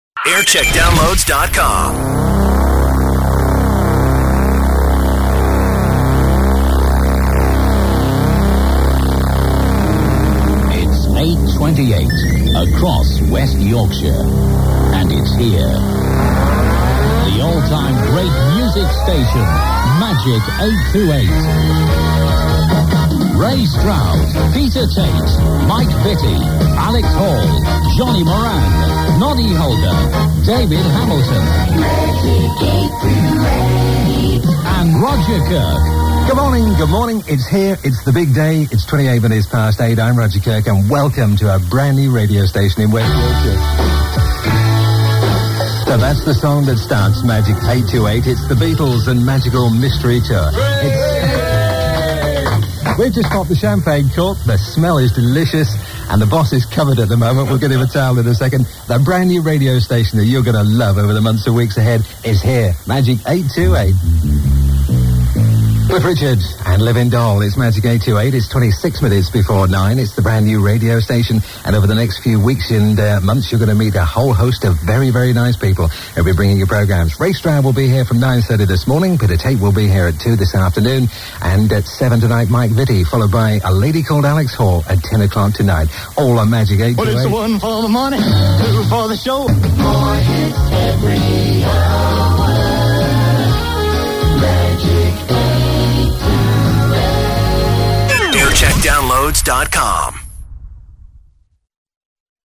Station Launch